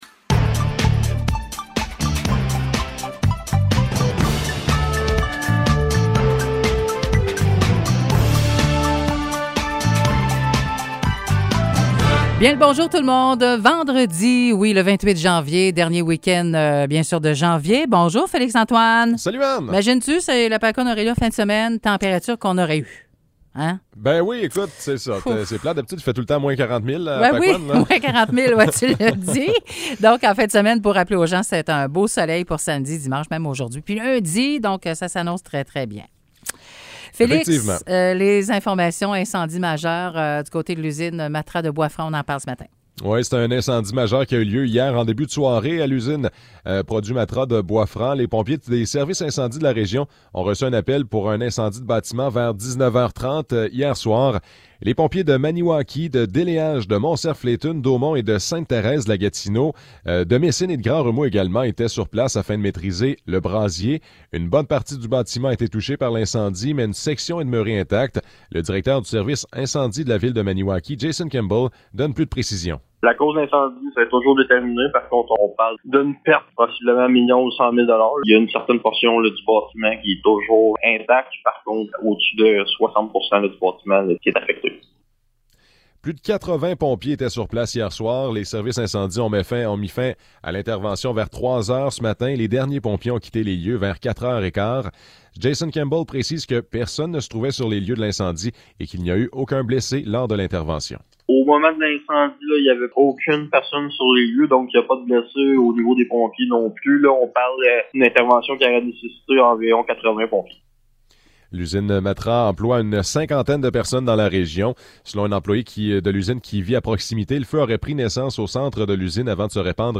Nouvelles locales - 28 janvier 2022 - 9 h